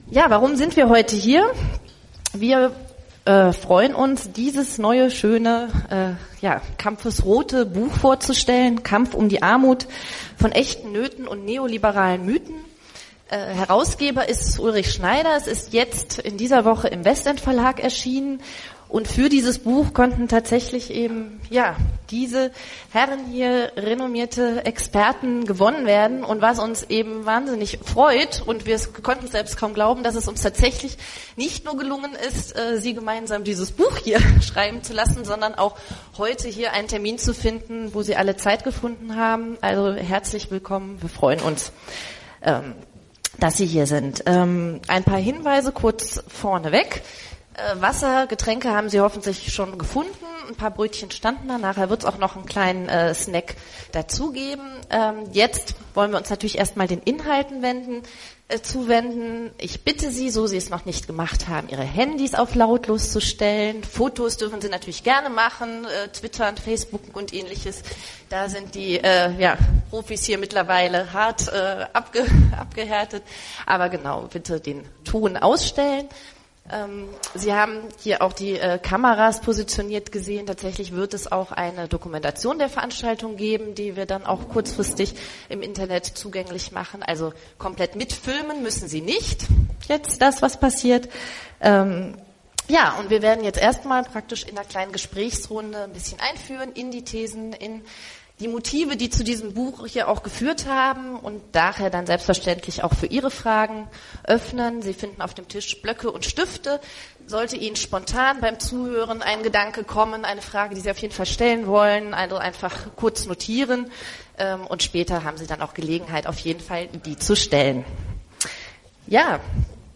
Die Diskussion ist hier nachzuhören!
Armut_Buchvorstellung_2015.mp3